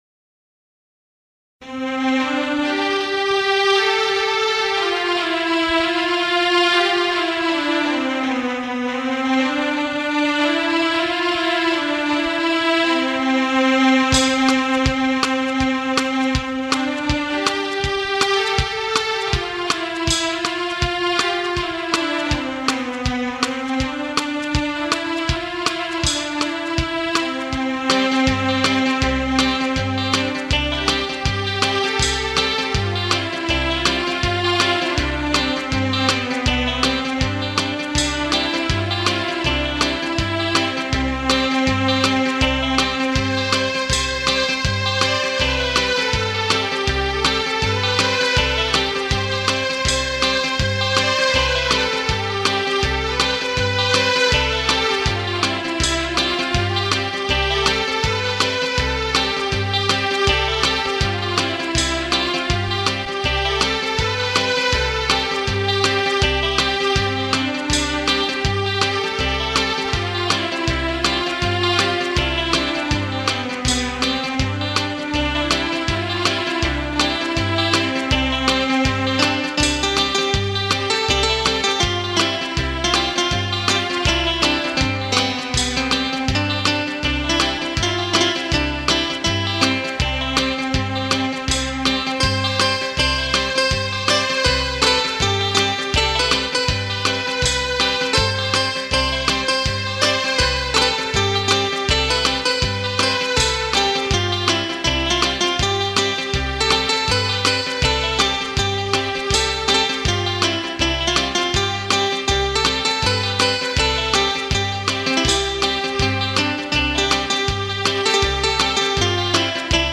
INSTRUMENTAL Section